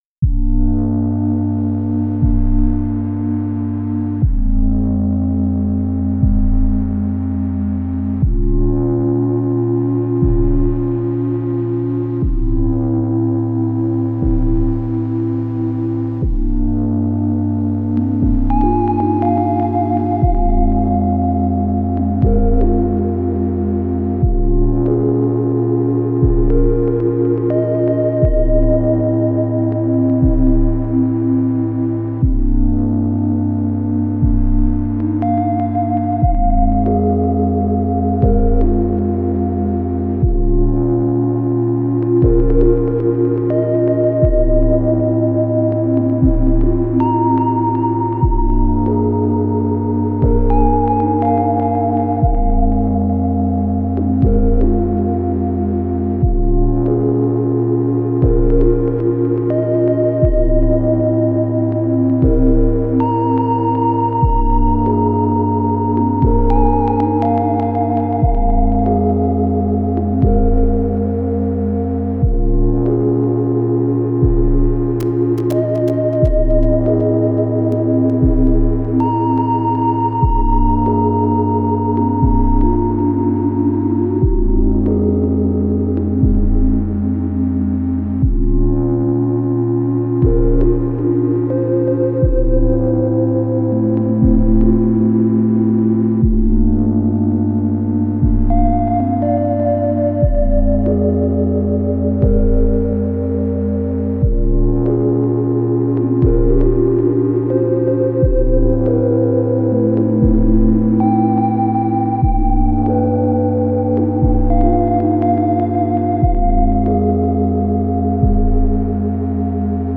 6 track piece, inspired by Trentemoller
First 3 tracks make a chord with ramp lfo on mod depth and going through the fx track for lpf.
There’s one deep kick track and two top lines with this glassy pristine sound, drenched in verb and delay.